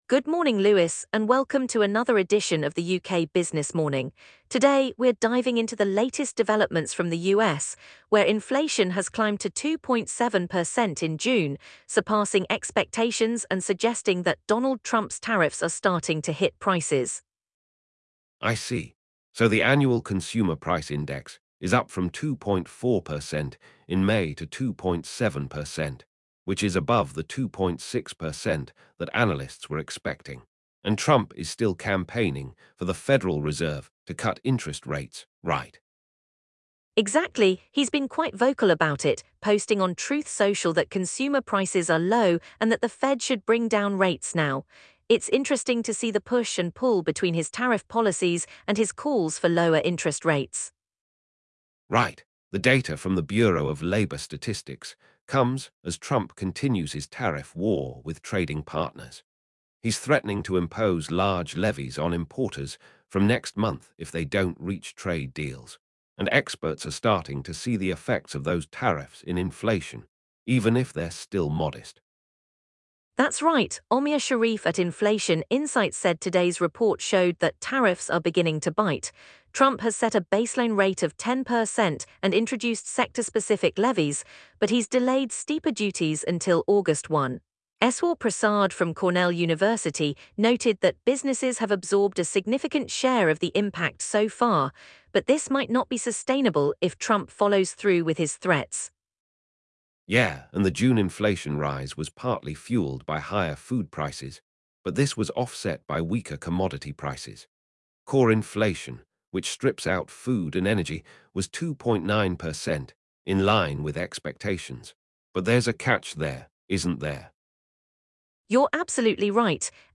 UK morning business news